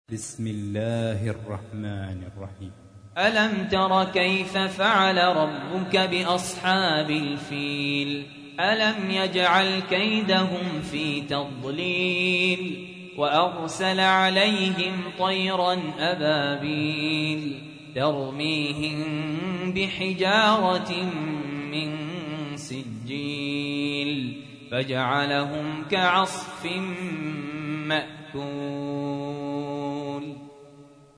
تحميل : 105. سورة الفيل / القارئ سهل ياسين / القرآن الكريم / موقع يا حسين